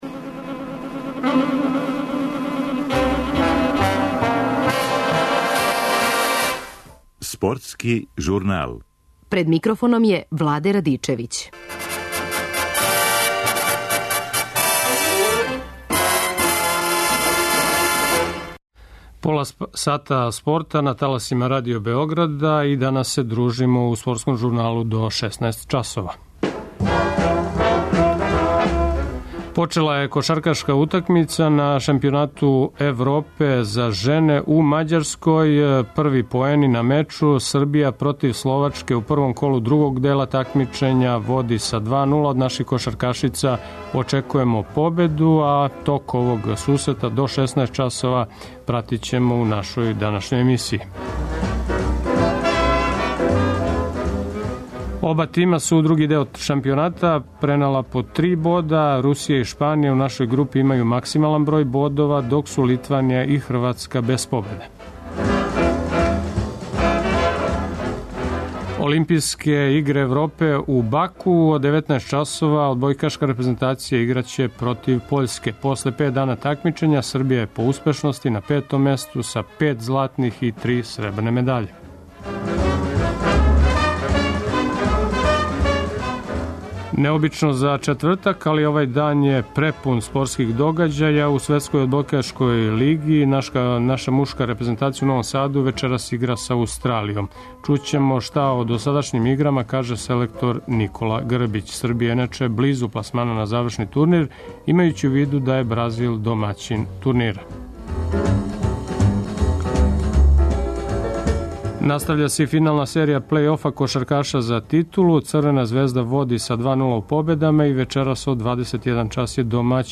Током емисије пратићемо утакмицу кошаркашица на шампионату Европе у Мађарској.